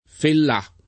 fallā⅜